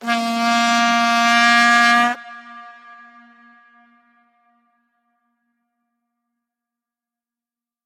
Звуки вувузелы
Здесь вы можете слушать и скачивать разные варианты гула: от монотонного гудения до интенсивного рева.
Одиночный с эффектом эха